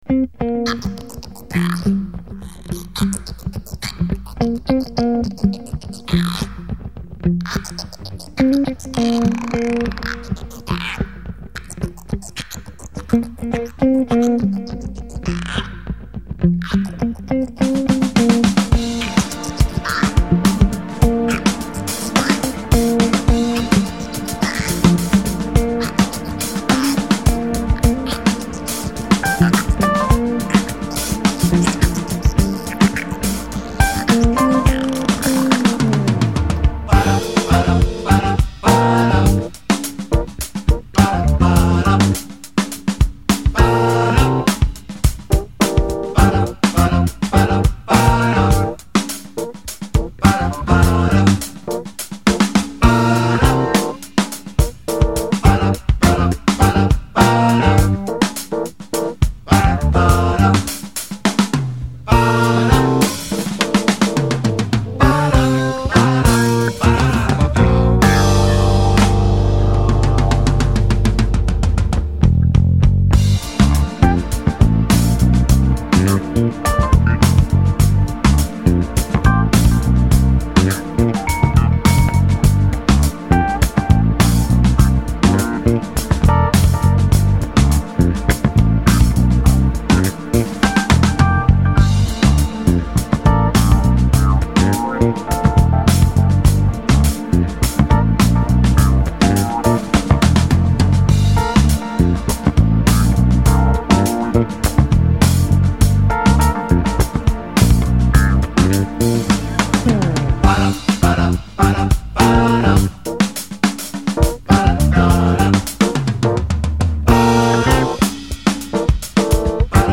Vocal!